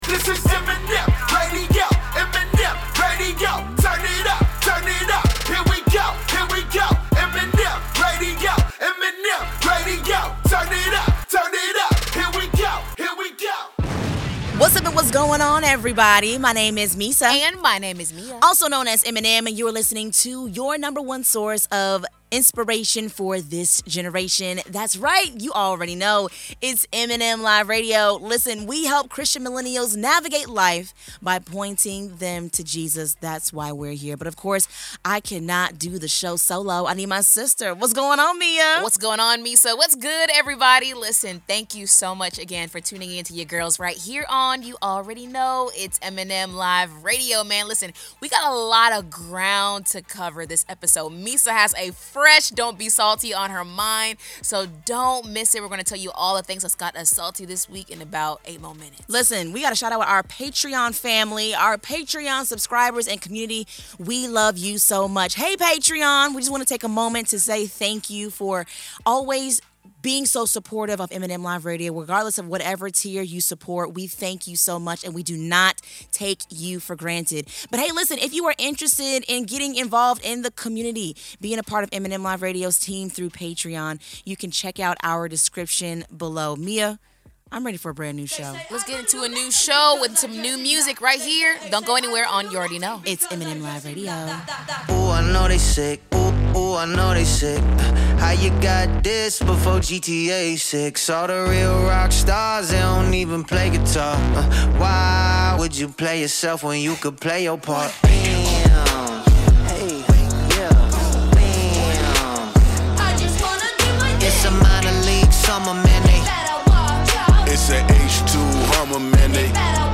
Through inspiring music, powerful stories, and thought-provoking interviews, this is the show where faith meets culture—all to point you back to Jesus.